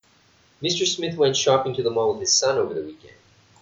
ディクテーションのサンプル音声